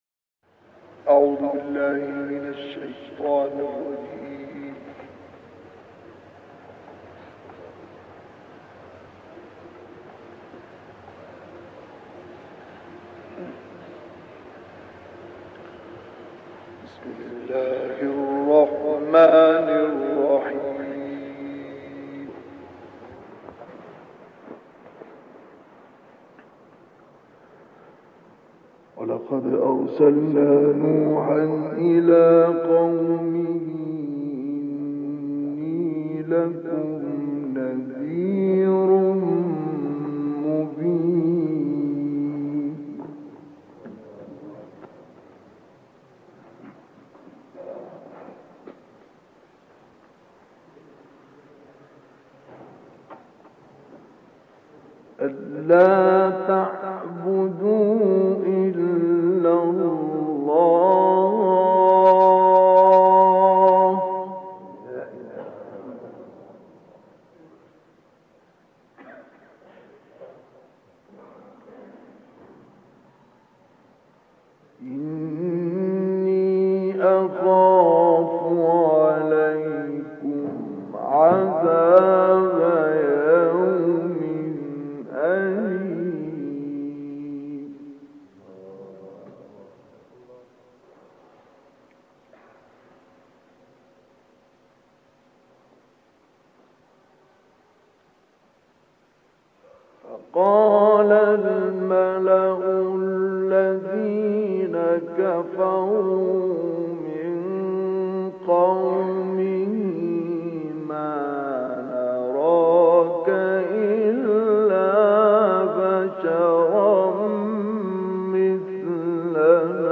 تلاوت سوره هود با صدای مصطفی اسماعیل+ دانلود/ بیان سرگذشت نوح نبی(ع)
گروه فعالیت‌های قرآنی: تلاوت آیات ۲۵ تا ۵۰ سوره هود با صدای مرحوم استاد مصطفی اسماعیل ارائه می‌شود.